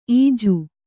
♪ īju